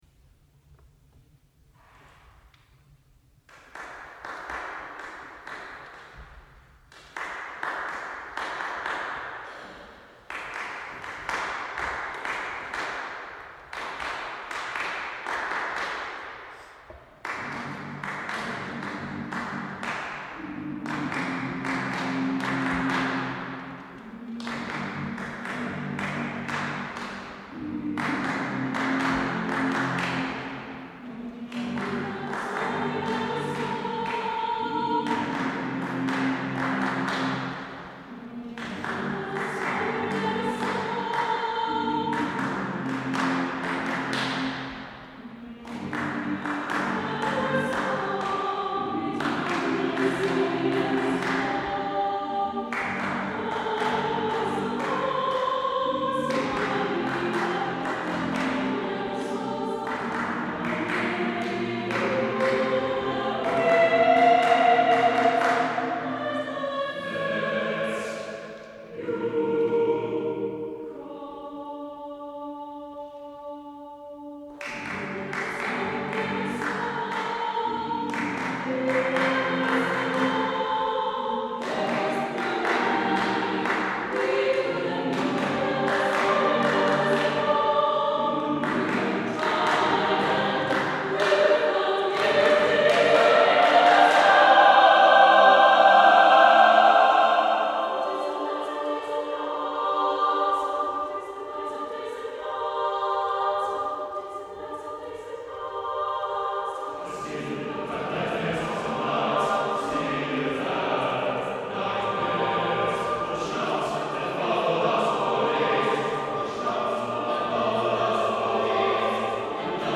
SATB div. a cappella